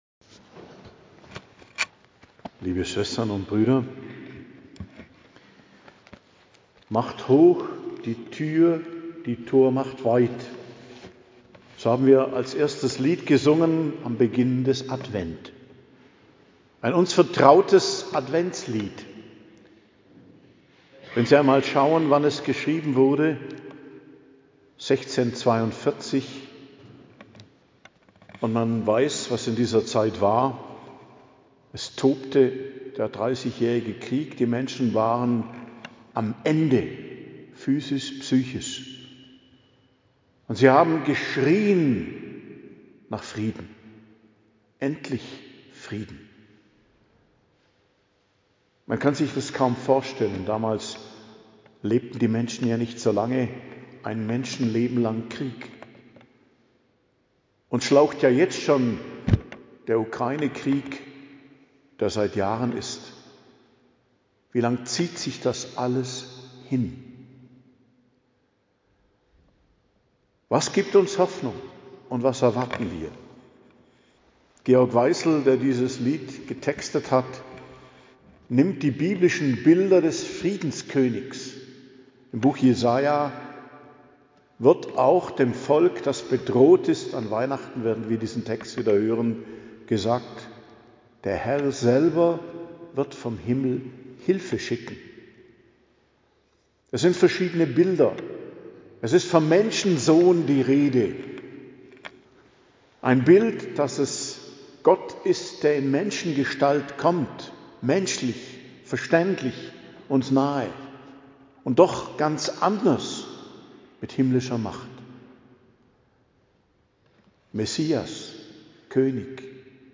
Predigt zum Ersten Adventssonntag, 30.11.2025 ~ Geistliches Zentrum Kloster Heiligkreuztal Podcast